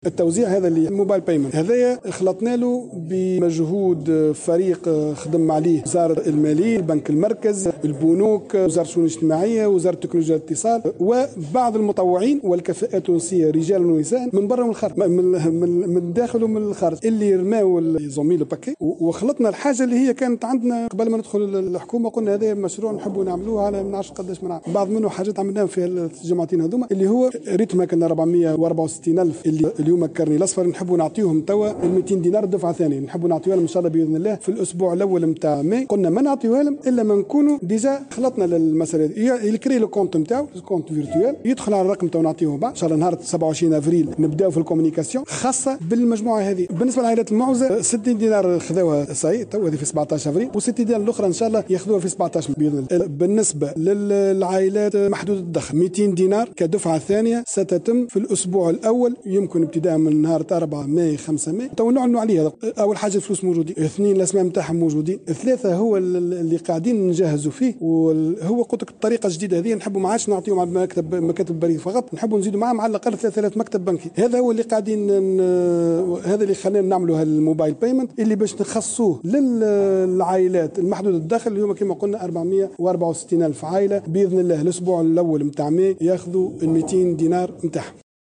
وأوضح خلال مؤتمر صحفي عقده، اليوم الخميس، أنه سيتمّ بداية من 4 ماي المقبل الشروع في صرف منحة جديدة بقيمة 200 دينار لفائدة العائلات المتكفلة بالأطفال والمسنين والأشخاص ذوي الإعاقة دون سند عائلي، إلى جانب صرف منحة بقيمة 200 دينار لفائدة العائلات محدودة الدخل المنتفعة ببطاقات العلاج بالتعريفة المنخفضة.